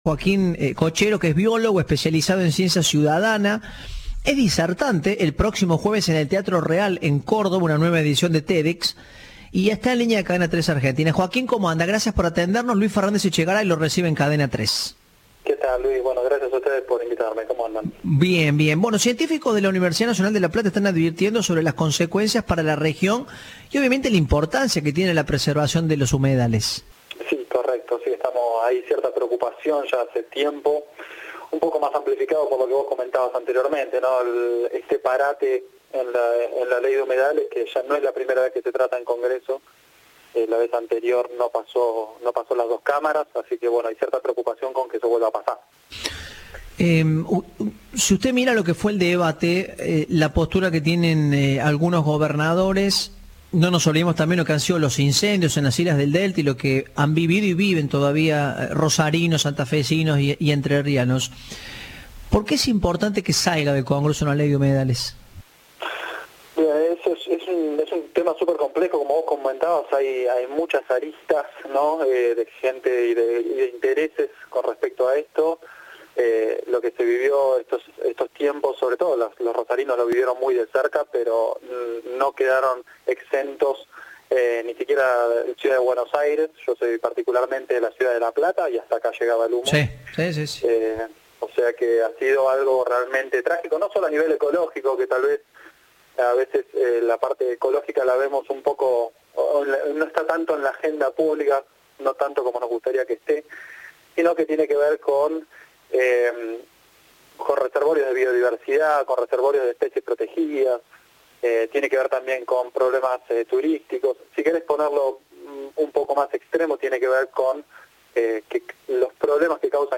En diálogo con Cadena 3, adelantó algunos de los temas que brindará en la charla.
Entrevista de "Informados, al regreso".